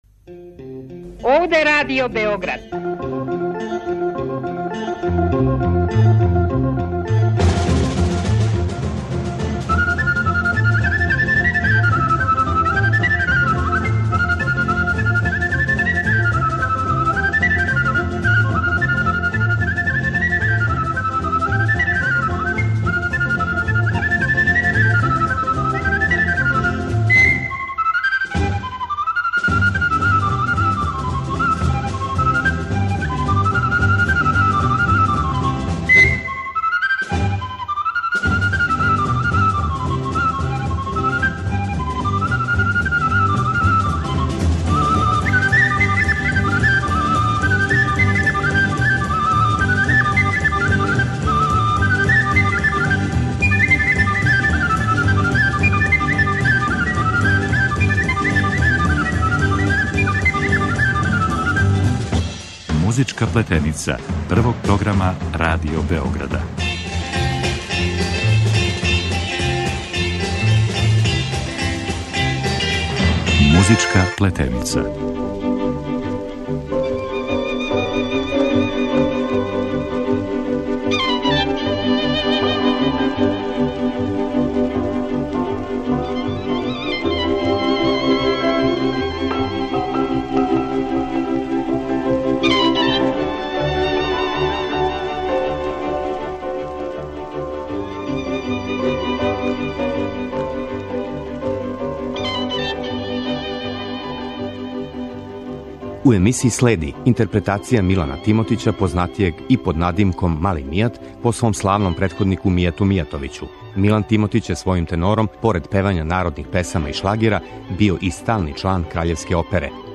Мелодија, хармонија и ритам представљају «триест» сваке музичке форме, самим тим и народне музике која је, у оставштини, углавном стицала форму варошке музике јер је најчешће звучно бележена тек у градовима где су је изводили познати инетрпретатори којима је пружена шанса да наступају на Радио-програму или снимају тада популарне плоче на 78 обртаја.